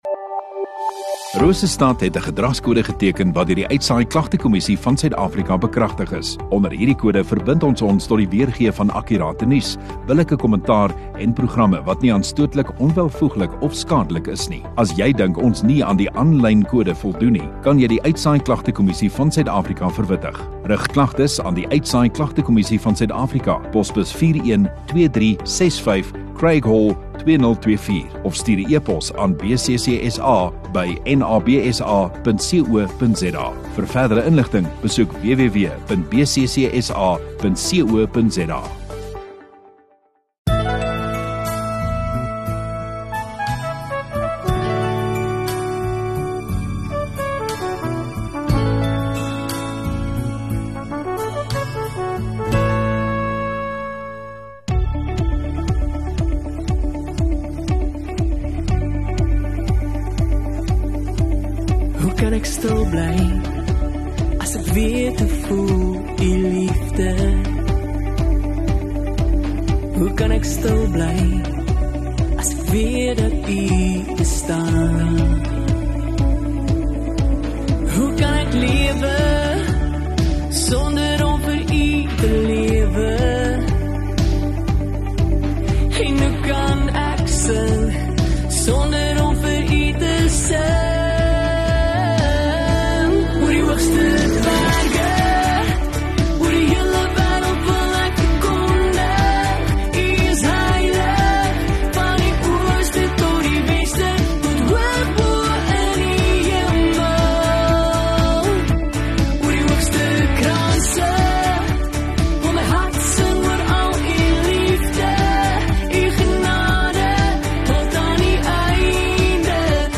8 Oct Sondagaand Erediens